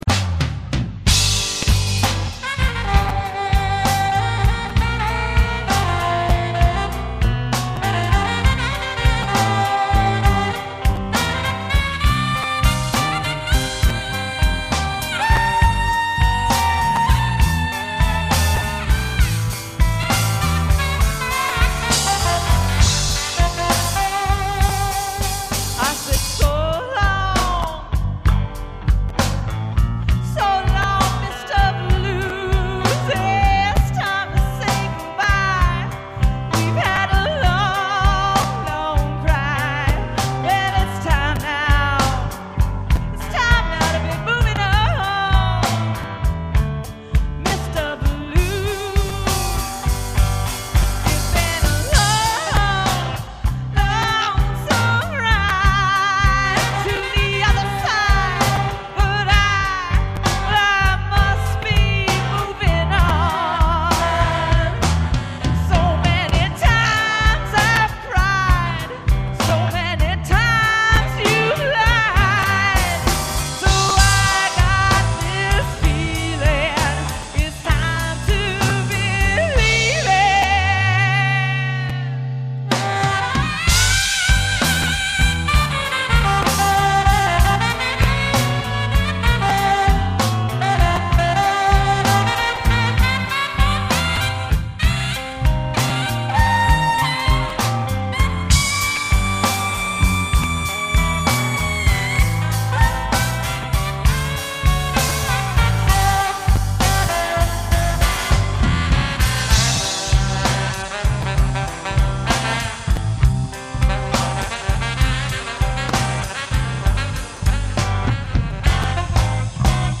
a blues song naturally